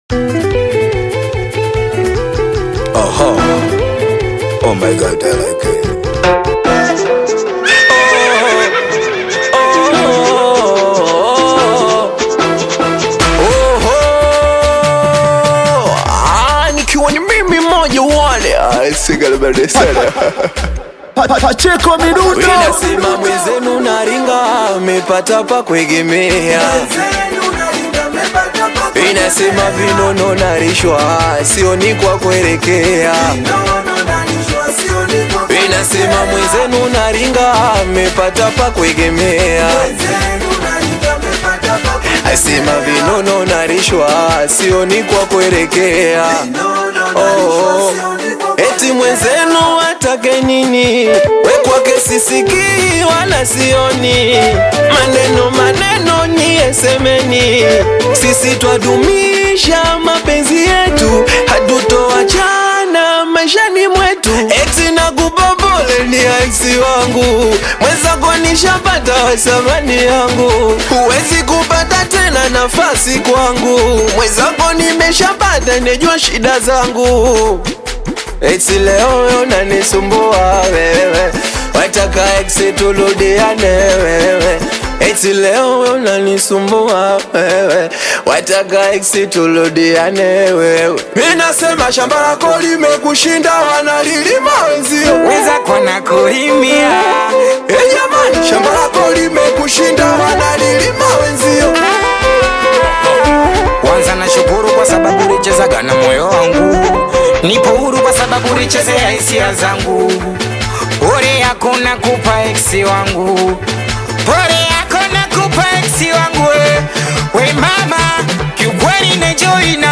Tanzanian Bongo Flava singeli
Singeli love song